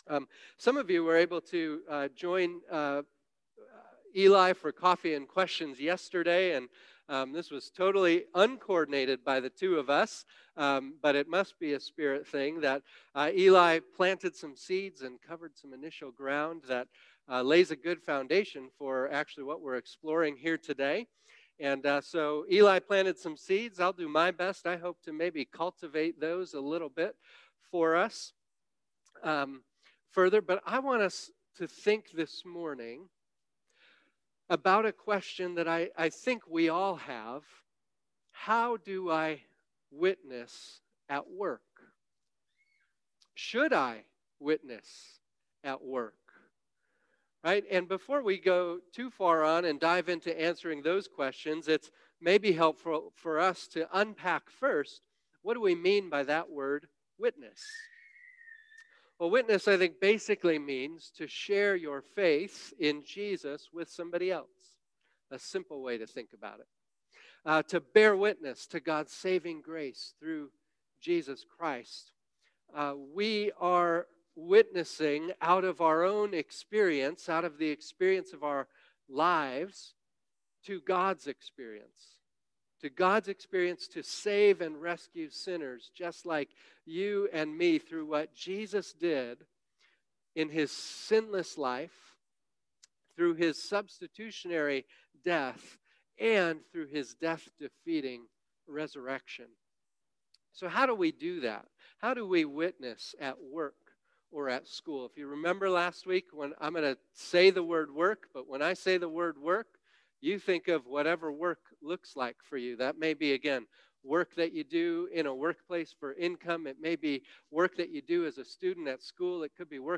Today’s message reflects on Matthew 5:14-16, 2 Corinthians 5:17-20 & 1 Peter 3:13-15.